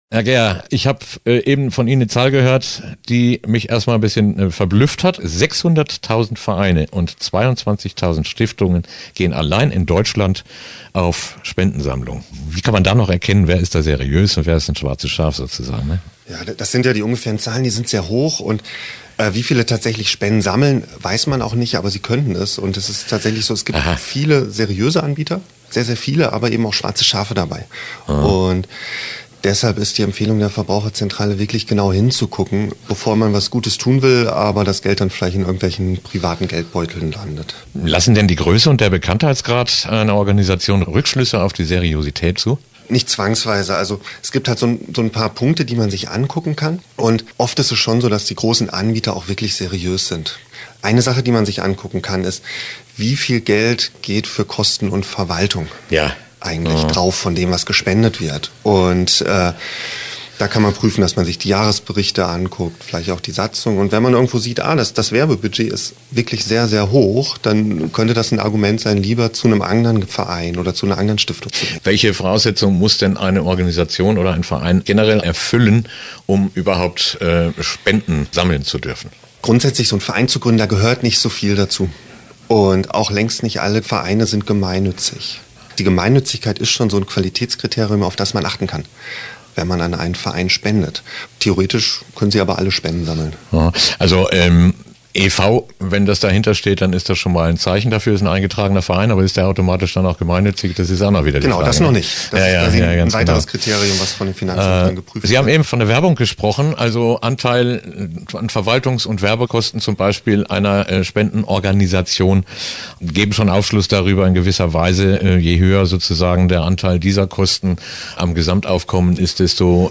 Interview-Spenden.mp3